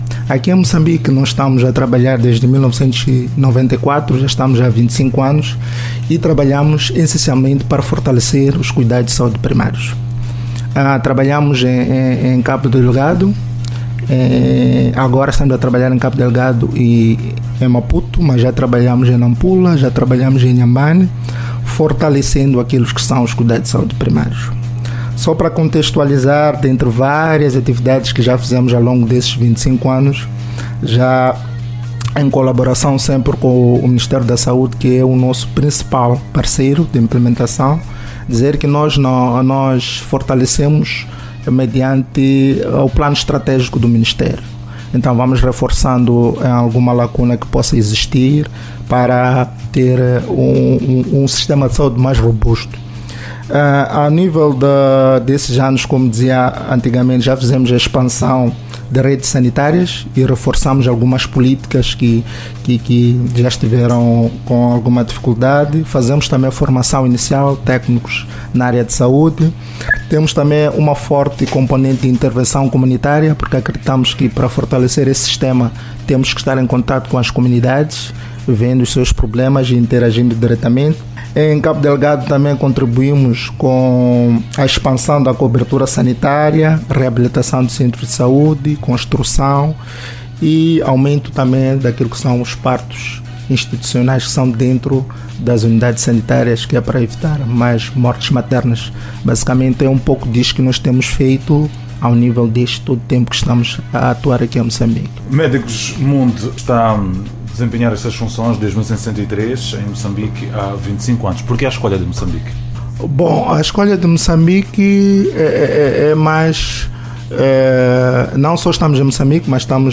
¿Cómo trabaja medicusmundi para fortalecerla, promoverla y defenderla? Todo esto y más en una entrevista de radio.
corte-entrevista-radio-indico-6-mb.mp3